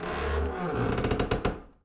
pgs/Assets/Audio/Doors/door_A_creak_06.wav at master
door_A_creak_06.wav